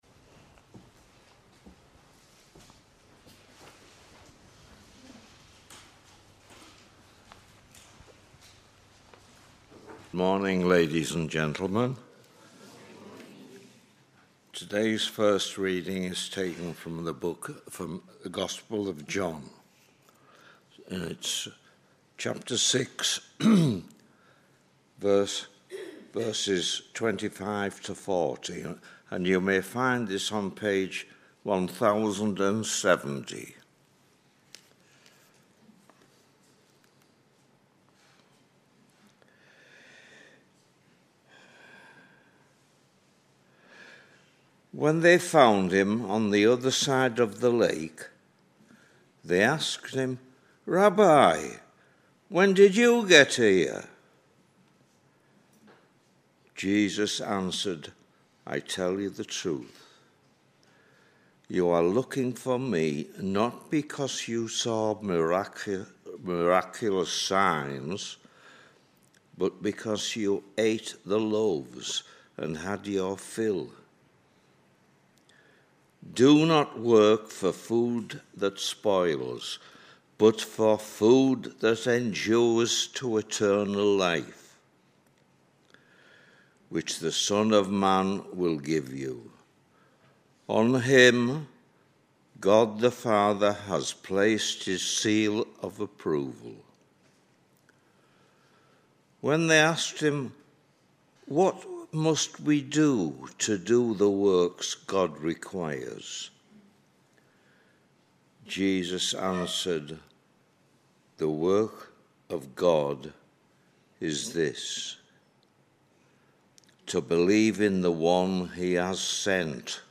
Readings-and-Sermon-on-9th-February-2025.mp3